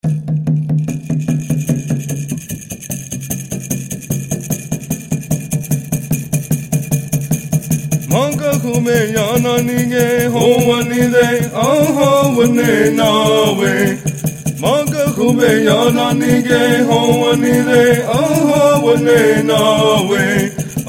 4.Ponca Song